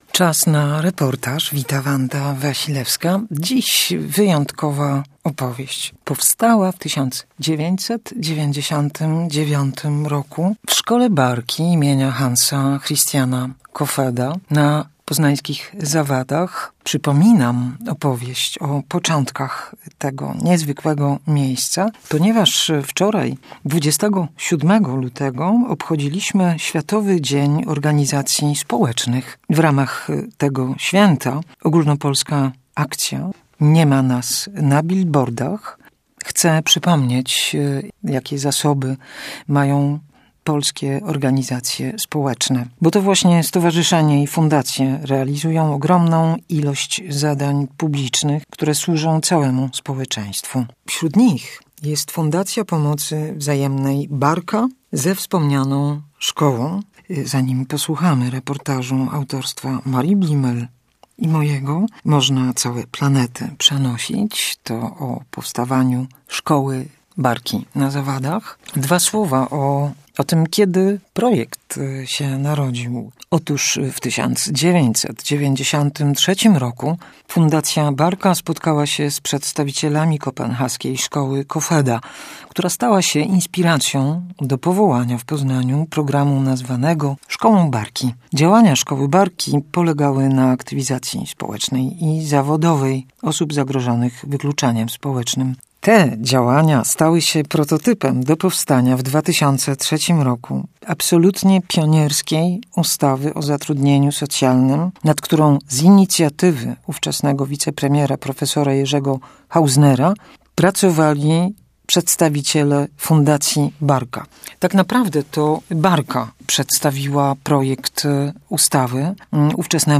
Czas na reportaż 28.02.2026